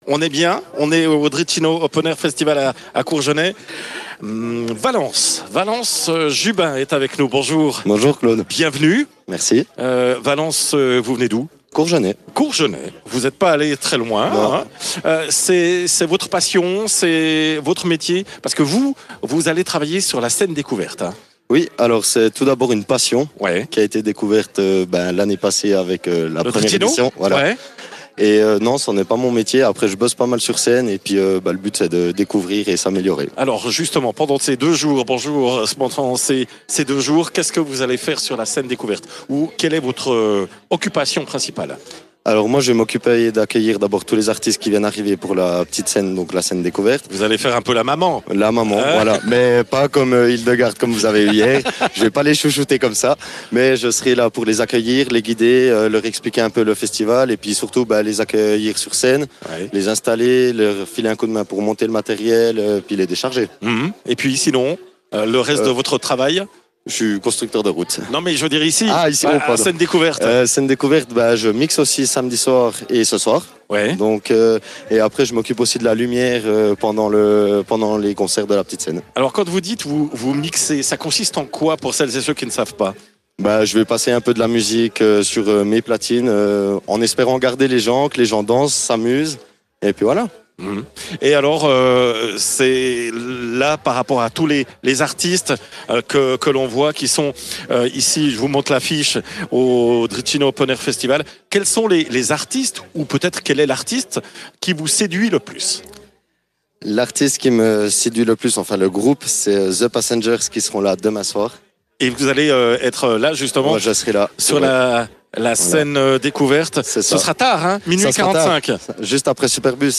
en direct du Dritchino Open Air Festival.